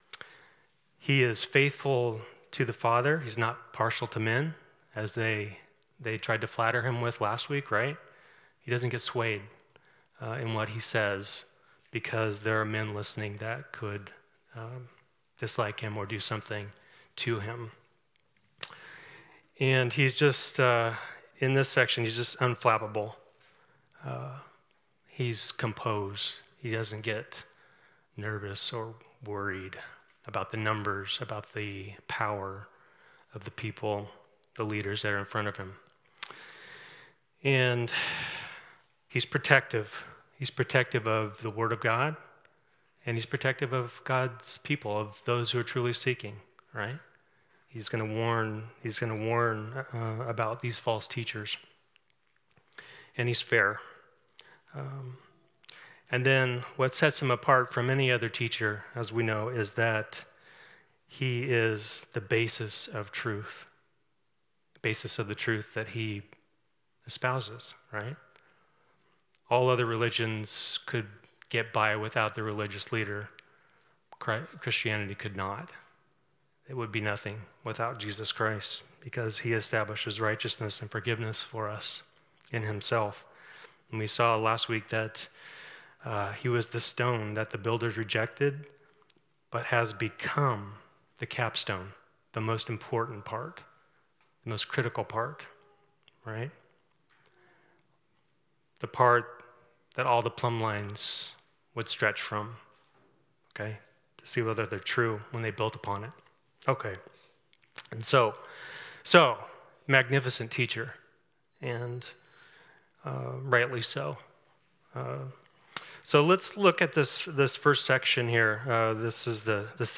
Passage: Mark 12:18-44 Service Type: Sunday School